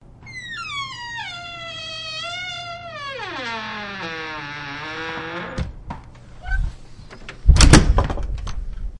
关门吱吱声关门声 (3)
描述：吱吱作响的门砰地关上了。
Tag: 吱吱声